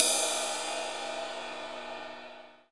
CYM RIDE30ML.wav